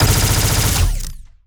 Added more sound effects.
GUNAuto_Plasmid Machinegun Burst_07_SFRMS_SCIWPNS.wav